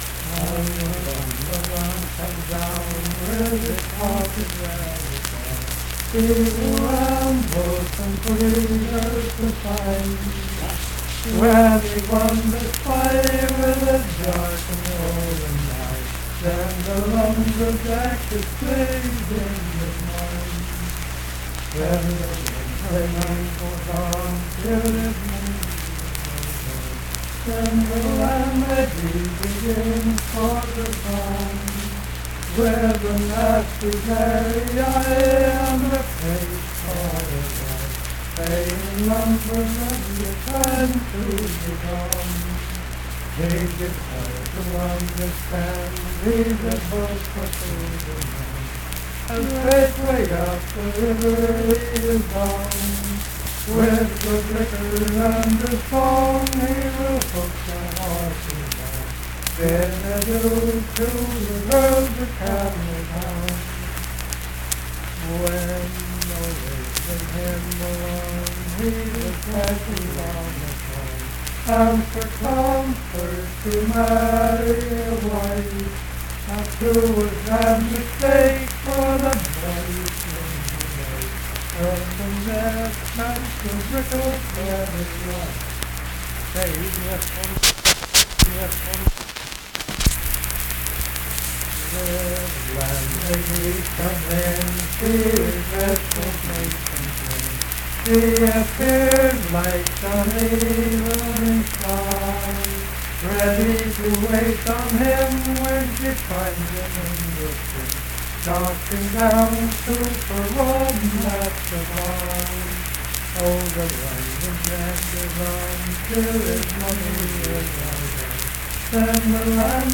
Unaccompanied vocal music
Richwood, Nicholas County, WV.
Voice (sung)